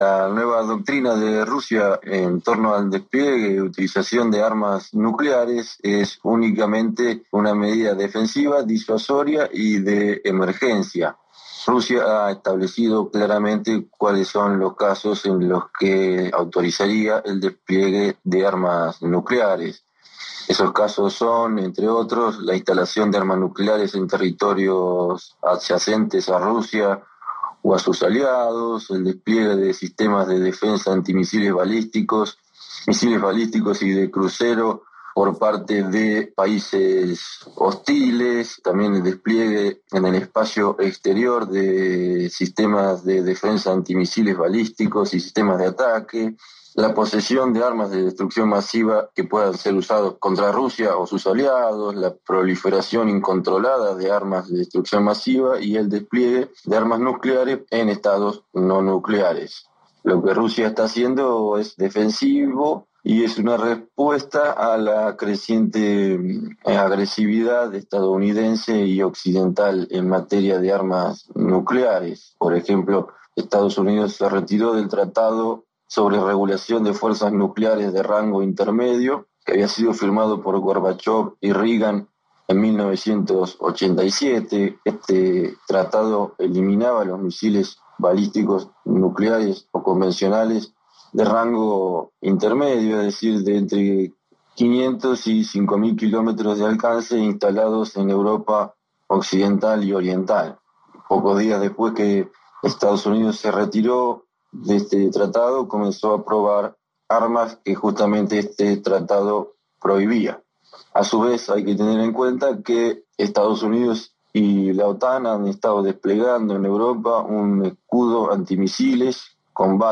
en declaraciones al Programa Radial Octavo Mandamiento